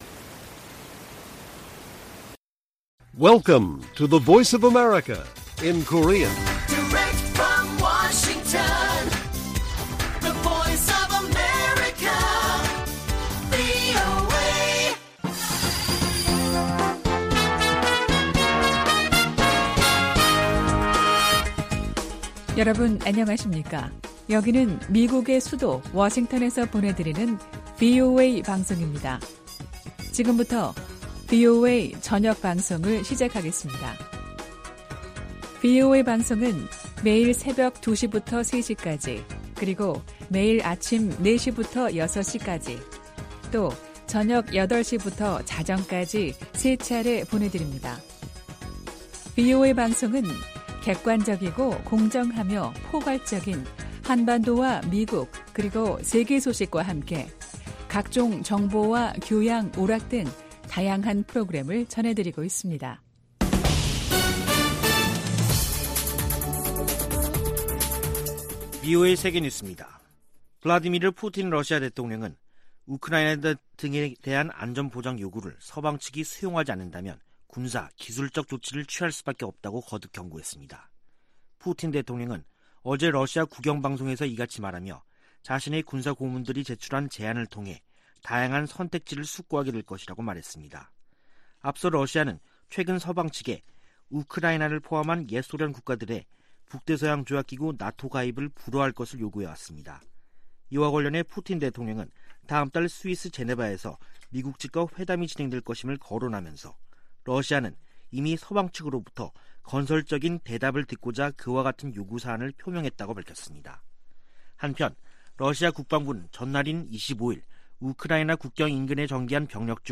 VOA 한국어 간판 뉴스 프로그램 '뉴스 투데이', 2021년 12월 27일 1부 방송입니다. 2022년 새해를 앞두고 조 바이든 미국 행정부의 대북 전략에 대한 전문가들의 전망과 제언이 이어지고 있습니다.